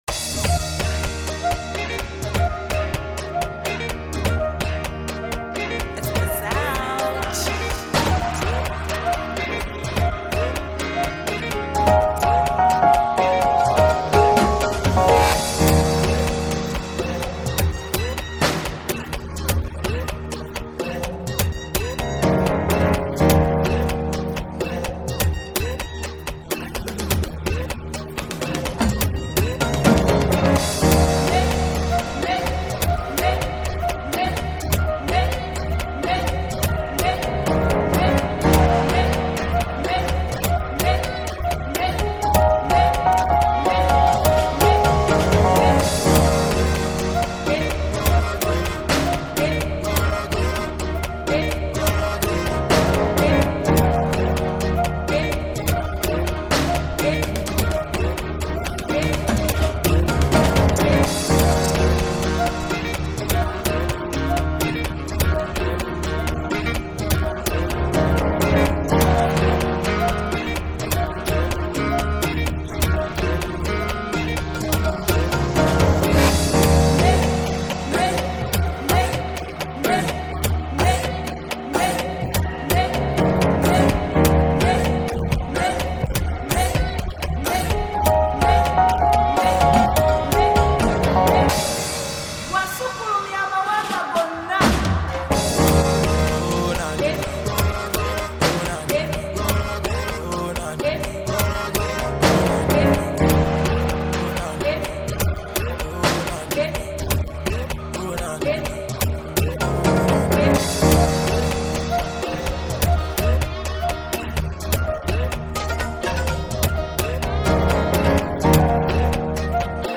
instrumental is the edited version